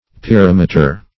Search Result for " pirameter" : The Collaborative International Dictionary of English v.0.48: Pirameter \Pi*ram"e*ter\, n. [Gr.